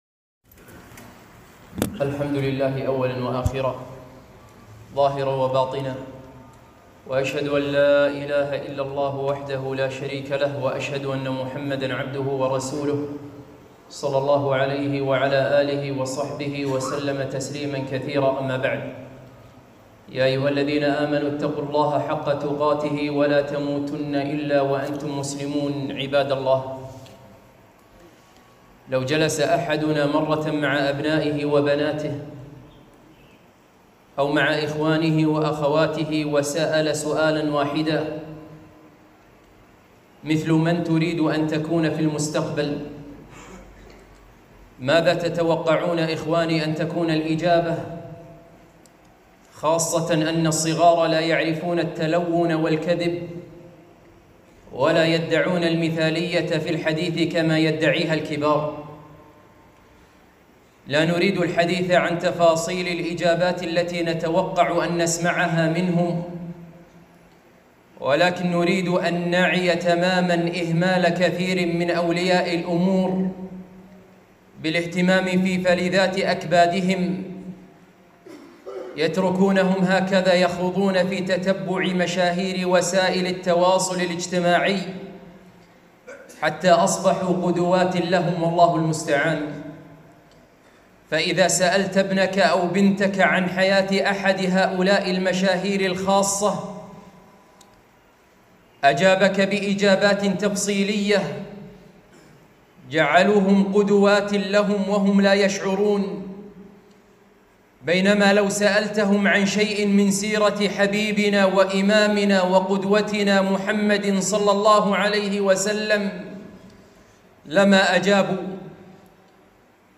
1- خطبة - ولادة النبي صلى الله عليه وسلم